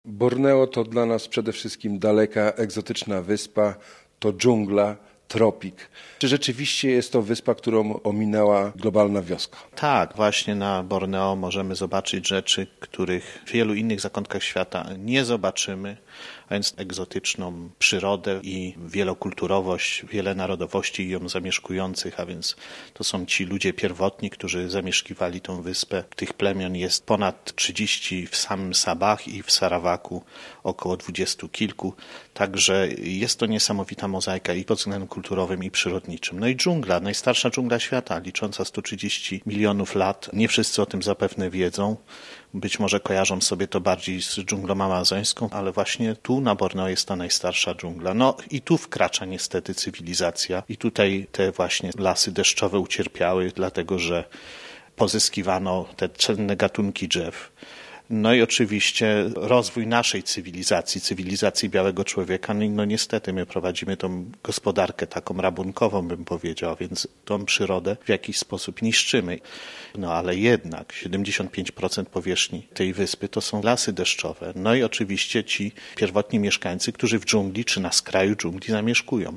Radiowe opowieści z kraju, w którym tropikalna dżungla splata się z nowoczesną technologią komputerową.